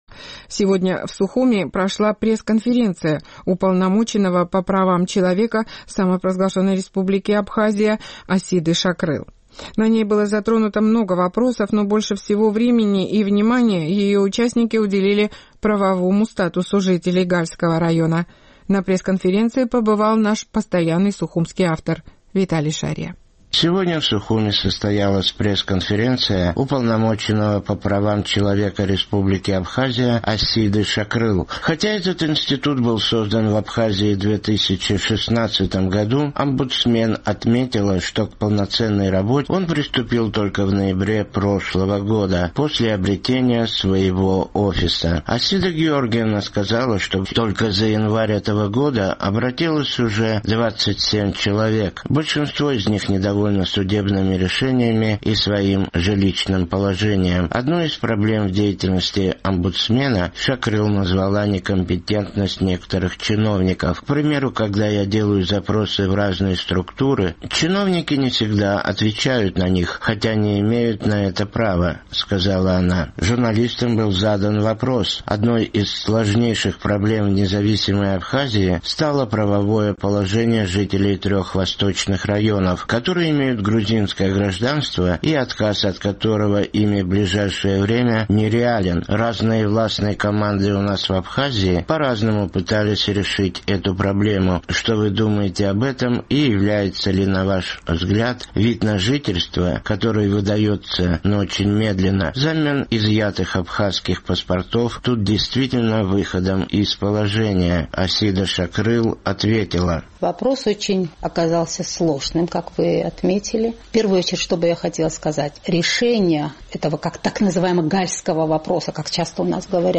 Сегодня в Сухуме состоялась пресс-конференция Уполномоченного по правам человека Республики Абхазия Асиды Шакрыл.
Асида Шакрыл ответила: